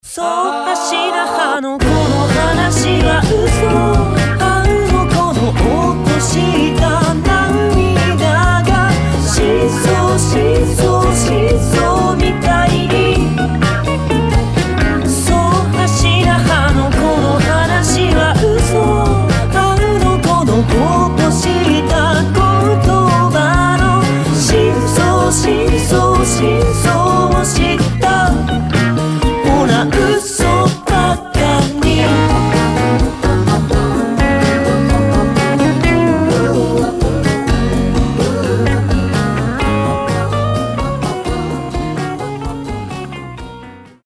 • Качество: 320, Stereo
гитара
мужской вокал
веселые
спокойные
Pop Rock
Спокойный японский поп-рок